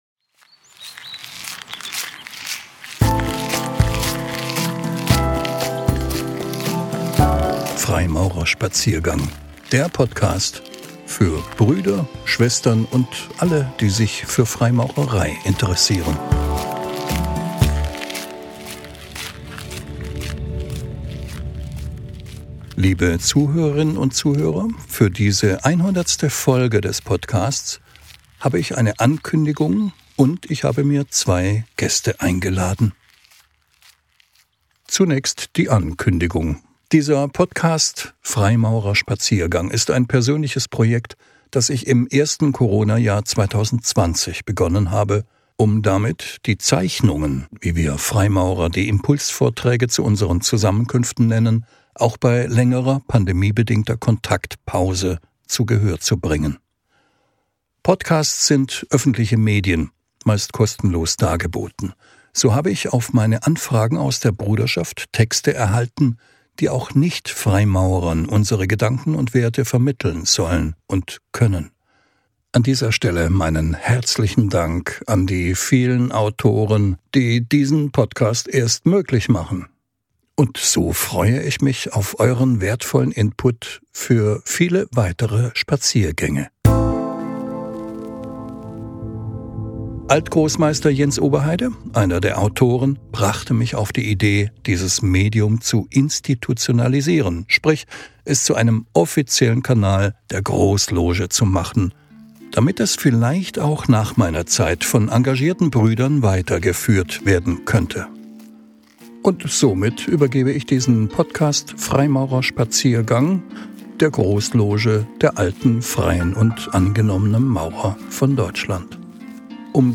Sonderfolge 100: Neuer Schirmherr und ein Interview ~ Freimaurer-Spaziergang Podcast